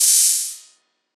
Open Hats
SOUTHSIDE_open_hihat_so_juicy.wav